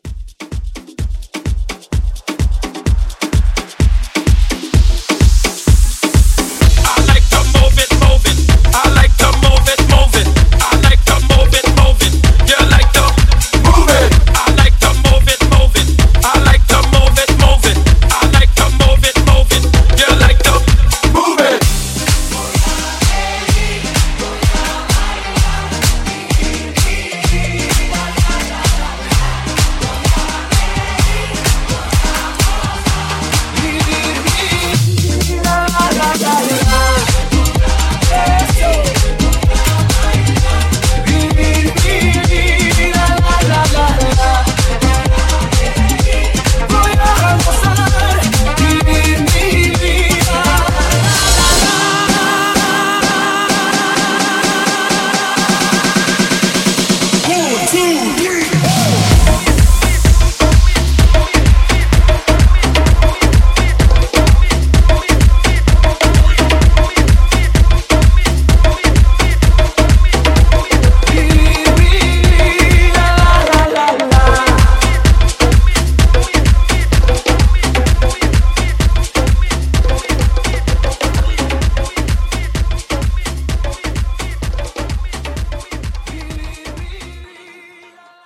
Genre: 90's
Clean BPM: 116 Time